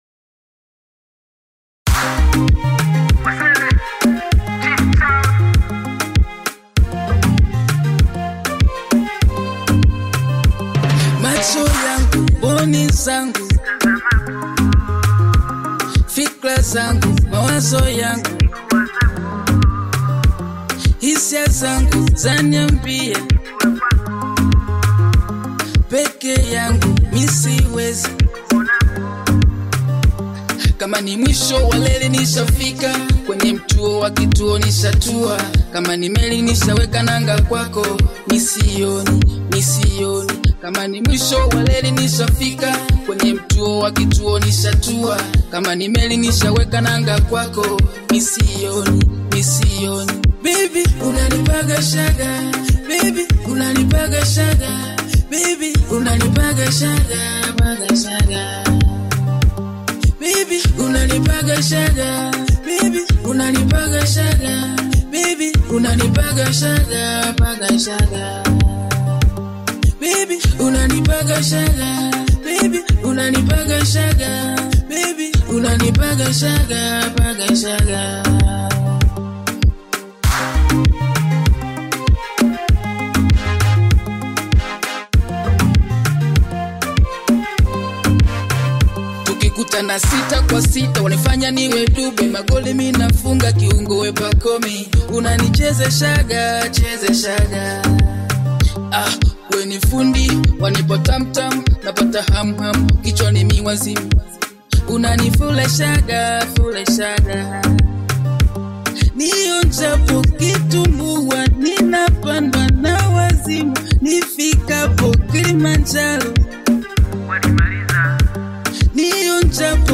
With its bold delivery and danceable tempo